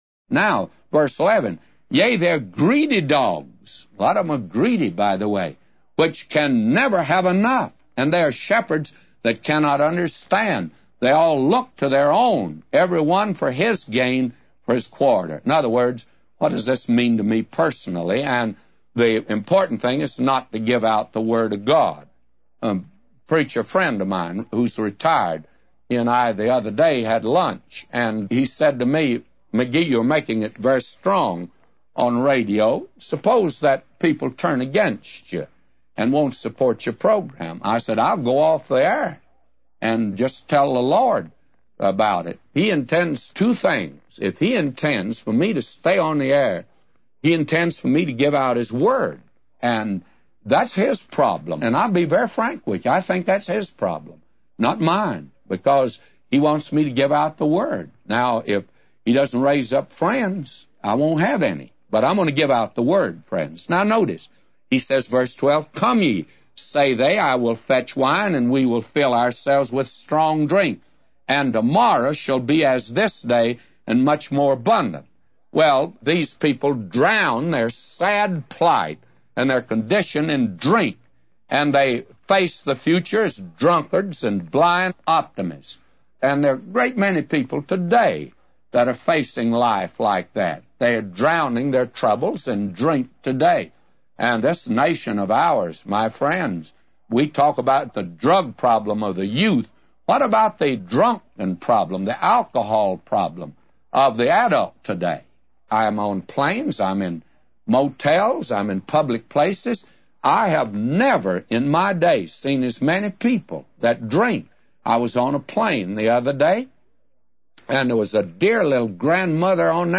A Commentary By J Vernon MCgee For Isaiah 56:11-999